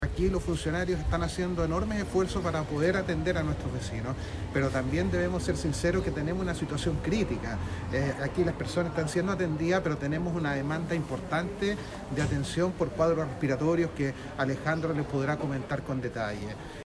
Sobre esto, el alcalde Denis Cortés Aguilera, señaló que